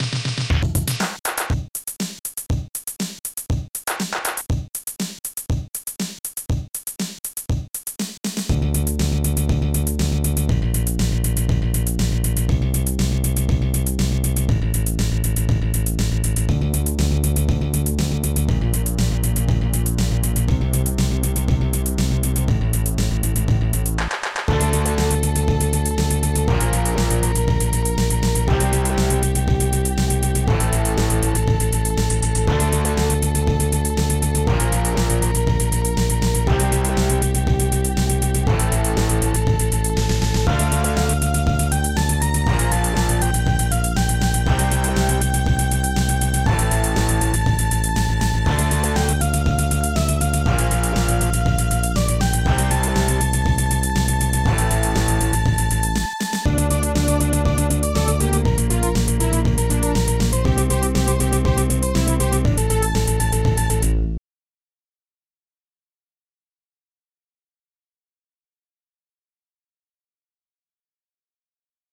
Protracker and family
Ultimate Soundtracker
st-01:strings6
st-01:slapbass
st-01:heavysynth
st-01:bassdrum3
st-01:popsnare2
st-01:hihat2
st-01:claps1
st-01:synbrass